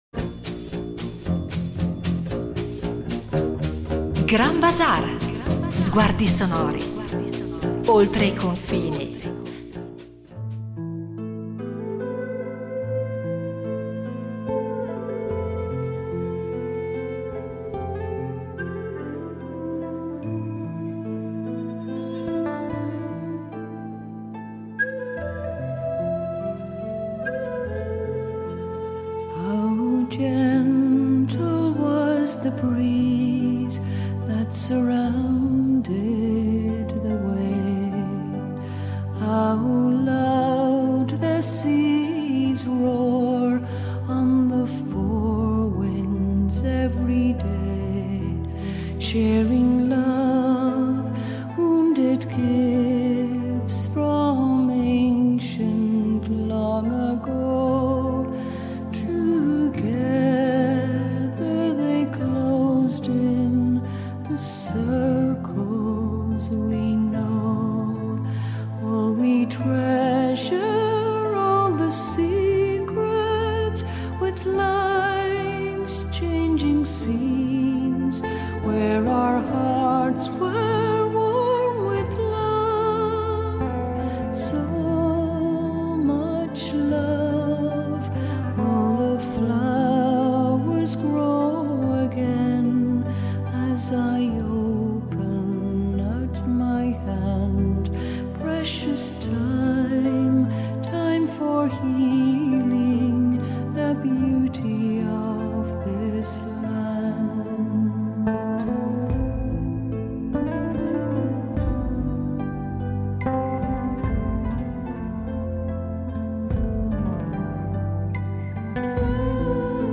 Insieme esploreranno i legami tra musica e immaginario fiabesco: ballate antiche, composizioni contemporanee, canti che evocano presenze sottili e misteriose, oltre a racconti, miti e leggende che da secoli dialogano con il suono. Due puntate che intrecciano ascolto, narrazione e suggestione poetica, tra repertori europei, tradizioni popolari, musica colta e atmosfere di confine.